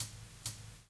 56BRUSHHH -R.wav